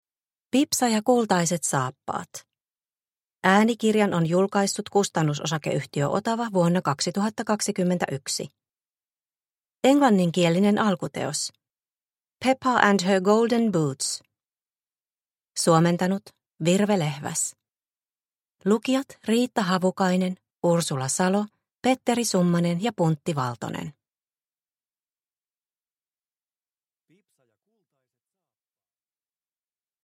Pipsa ja kultaiset saappaat – Ljudbok – Laddas ner